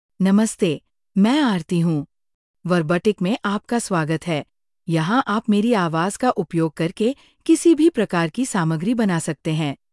Aarti — Female Hindi (India) AI Voice | TTS, Voice Cloning & Video | Verbatik AI
FemaleHindi (India)
Aarti is a female AI voice for Hindi (India).
Listen to Aarti's female Hindi voice.
Female
Aarti delivers clear pronunciation with authentic India Hindi intonation, making your content sound professionally produced.